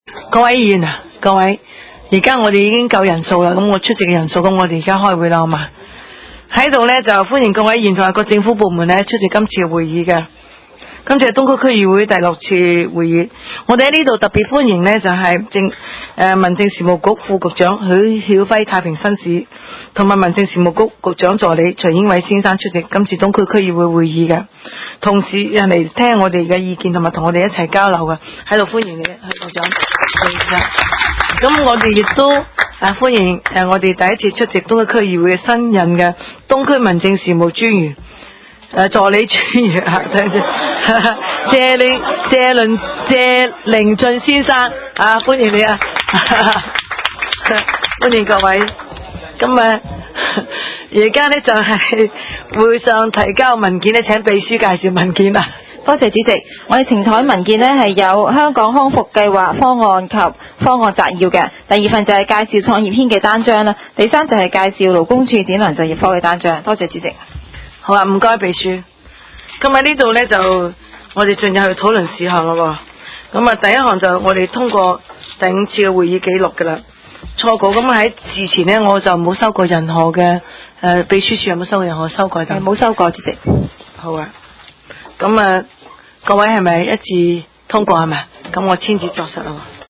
東區區議會會議室